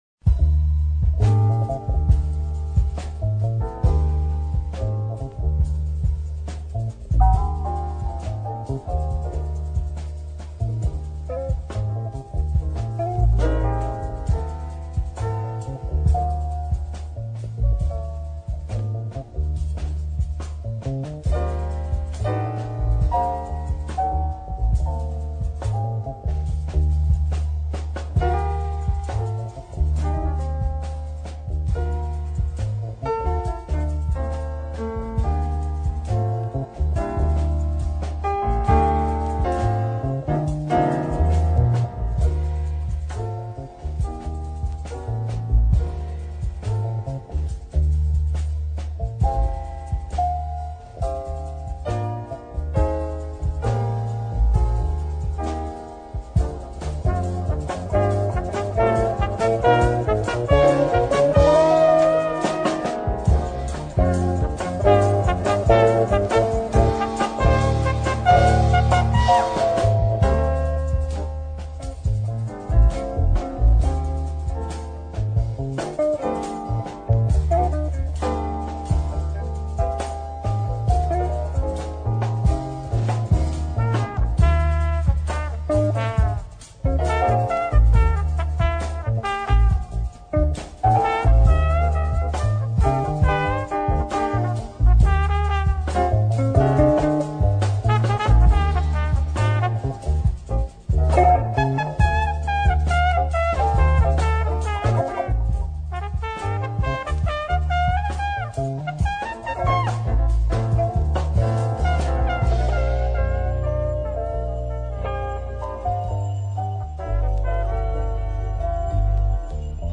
Deep Russian jazz on very long and beautiful tracks.